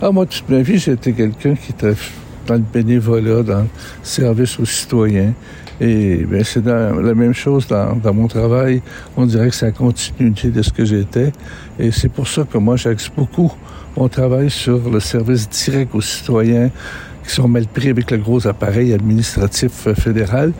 En entrevue, celui qui en était à ses 13e élections depuis 1984 a expliqué pourquoi il a trouvé celle-ci particulièrement exigeante.